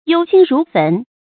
注音：ㄧㄡ ㄒㄧㄣ ㄖㄨˊ ㄈㄣˊ
憂心如焚的讀法